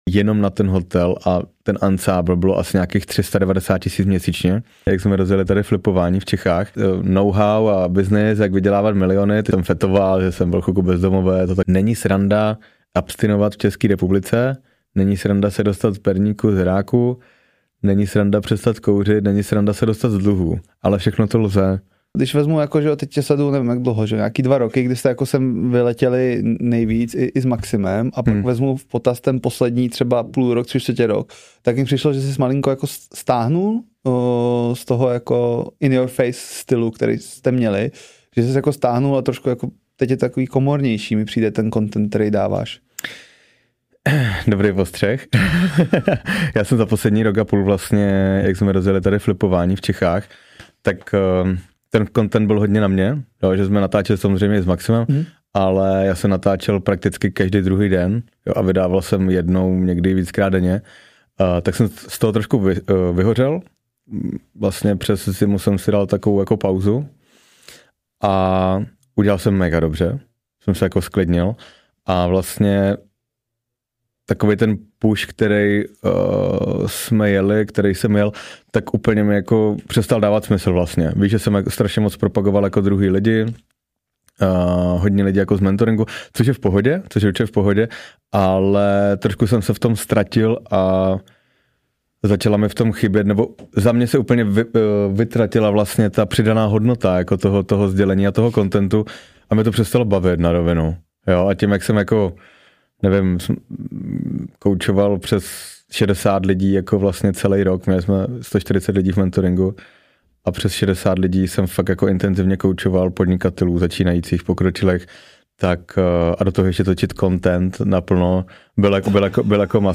V tomhle hlubokém a otevřeném rozhovoru jdeme až na dřeň. Mluvíme o bolesti, pádu, změně i duchovní cestě.